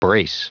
Prononciation du mot brace en anglais (fichier audio)
Prononciation du mot : brace